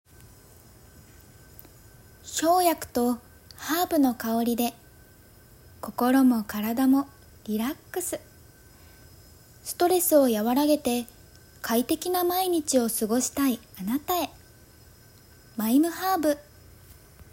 ボイスサンプル
CM２